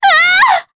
Juno-Vox_Damage_jp_03.wav